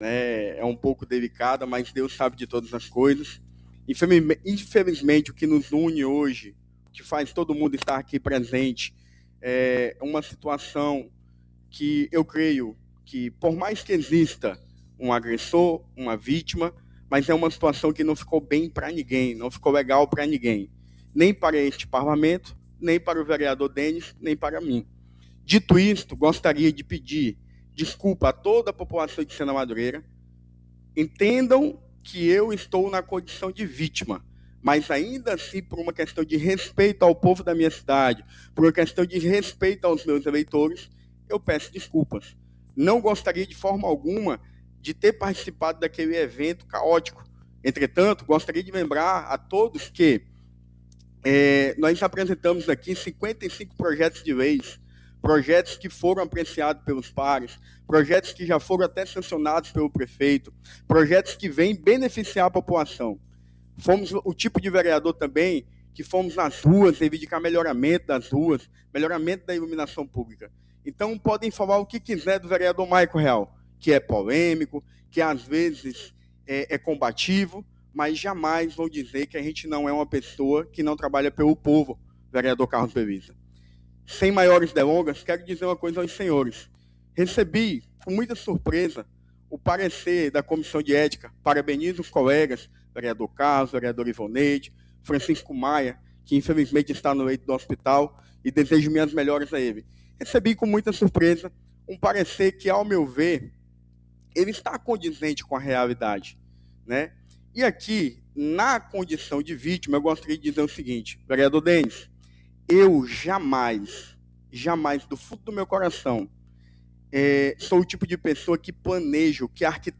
Durante a sessão extraordinária realizada na noite desta segunda-feira (23), na Câmara Municipal de Sena Madureira, o vereador Maycon Moreira (PSD) fez um pronunciamento marcado por pedido público de desculpas, reconhecimento dos erros e declaração de perdão ao vereador Denis Araújo (PP), após a briga ocorrida no plenário em 2025.